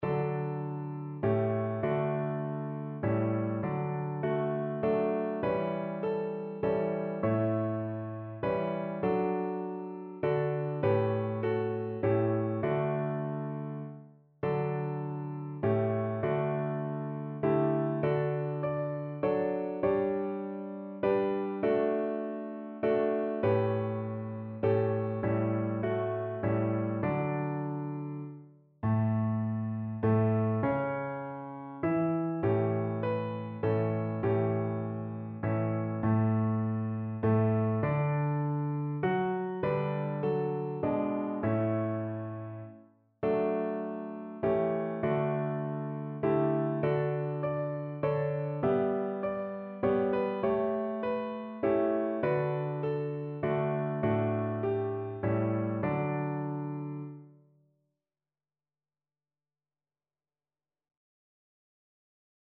Notensatz 1 (4 Stimmen gemischt)
• gemischter Chor [MP3] 974 KB Download